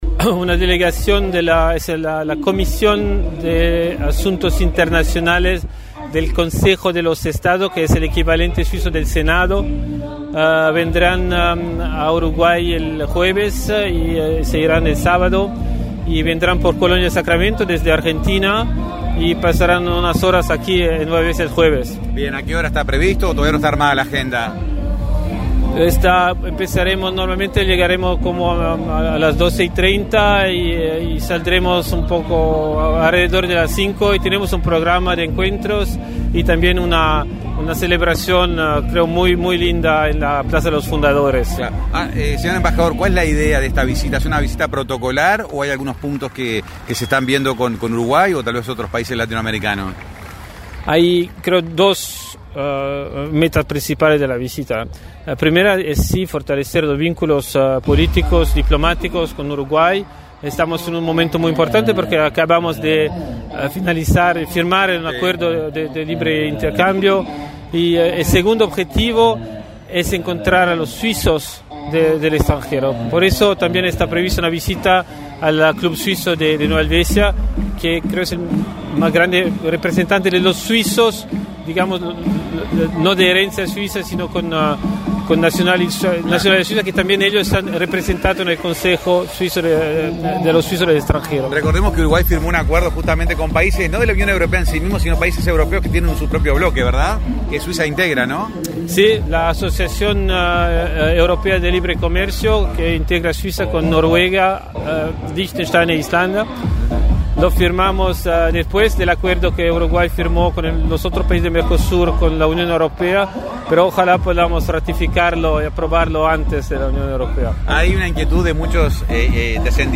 Amplía en detalles el embajador de Suiza en Uruguay y Paraguay, Álvaro Borghi.